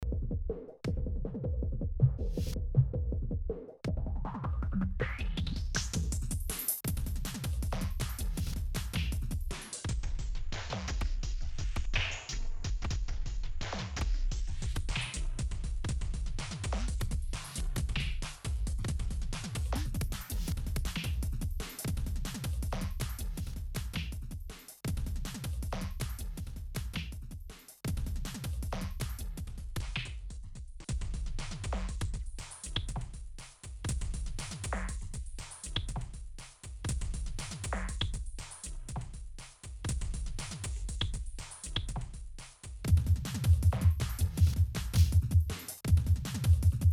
Poly rythm and nice sound parameter :hugs: